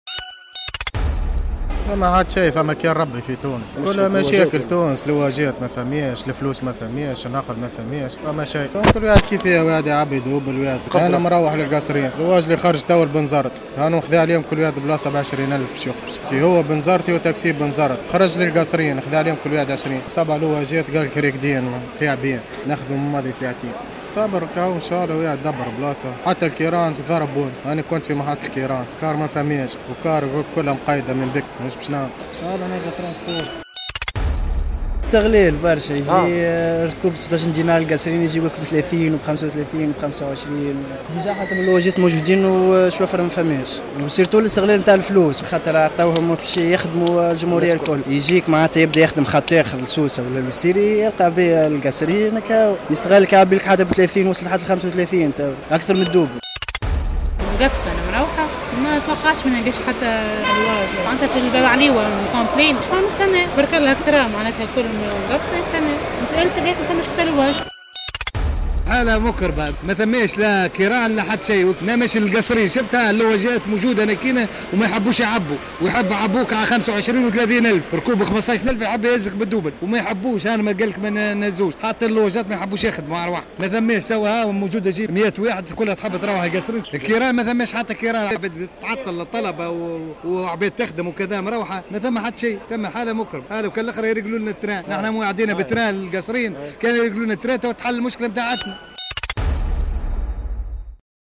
تذمر عدة مواطنين بمحطة المنصف باي بالعاصمة، في تصريح لمراسل الجوهرة اف ام، من عدم توفر وسائل النقل سواء حافلات أو "لواجات".